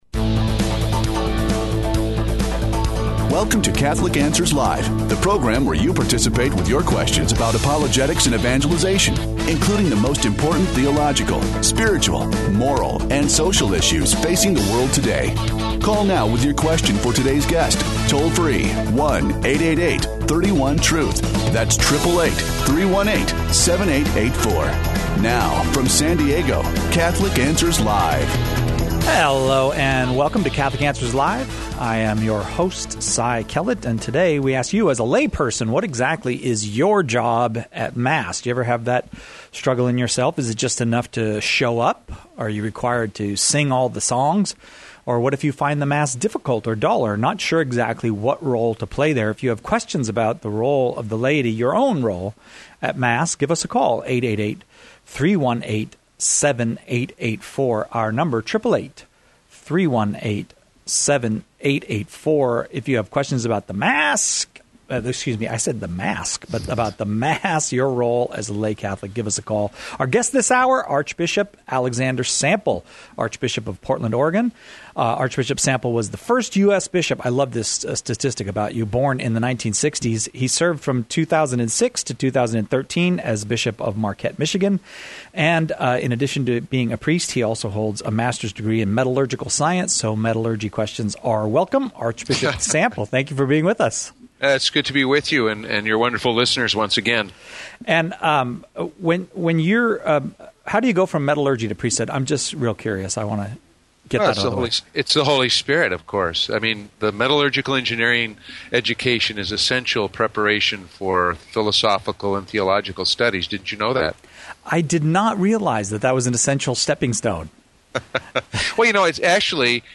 Portland’s archbishop joins us for a discussion of the Mass, its meaning, and what the laity are supposed to be doing while the Mass is being prayed.